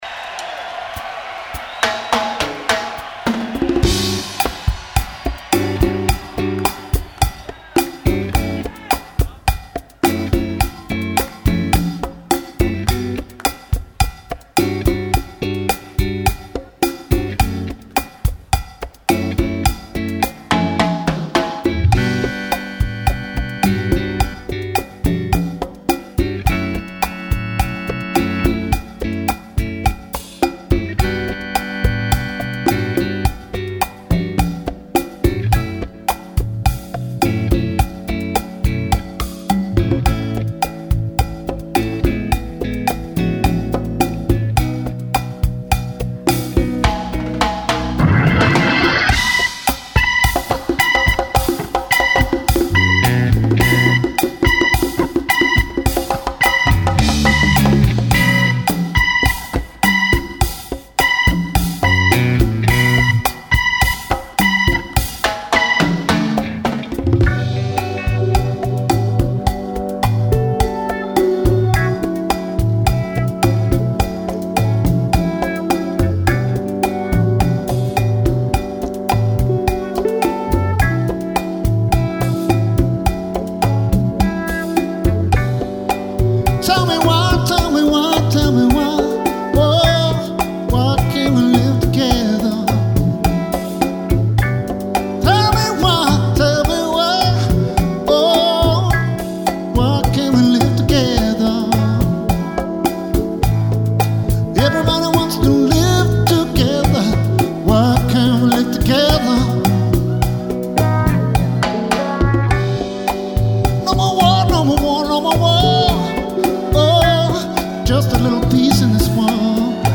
soul music
Hammond organ